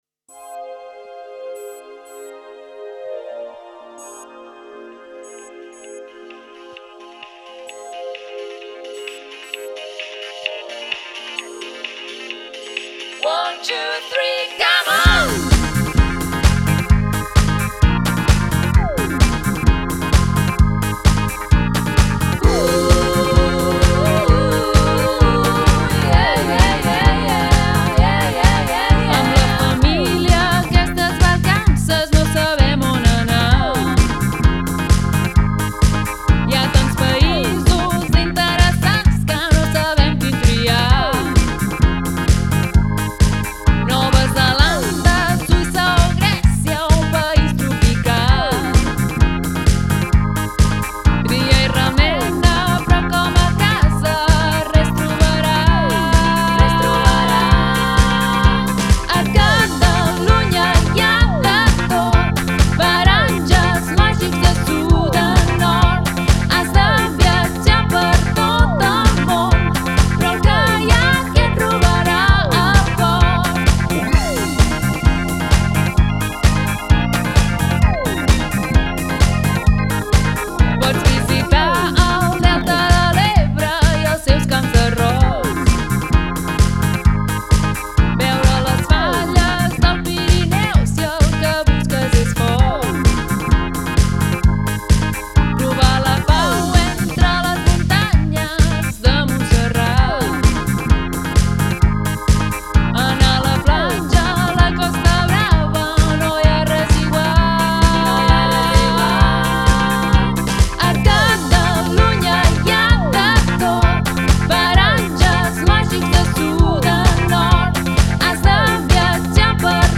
Versió amb veu: